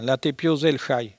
Langue Maraîchin
Patois